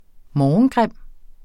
Udtale [ ˈmɒɒnˌgʁεmˀ ]